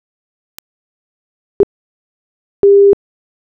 Figure 3 – A Dirac pulse, 30 ms sine burst, and 300 ms sine burst. All three have exactly the same amplitude (peak level) but different RMS levels.
Burst_comparison.wav